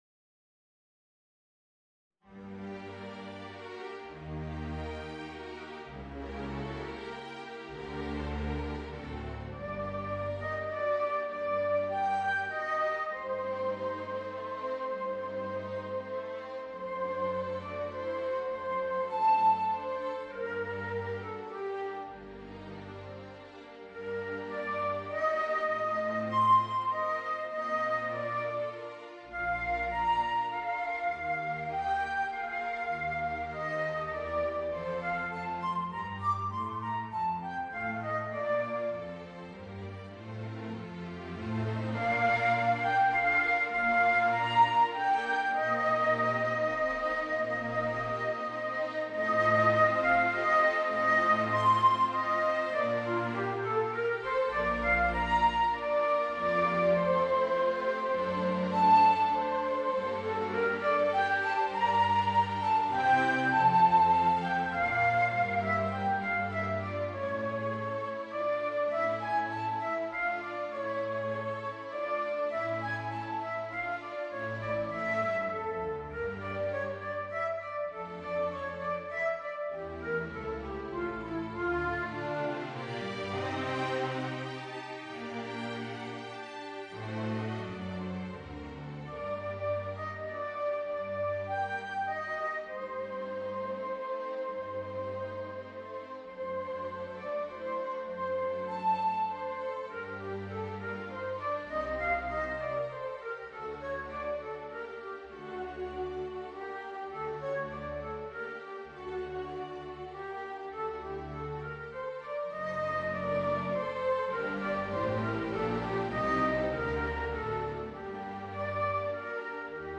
Voicing: Clarinet and String Orchestra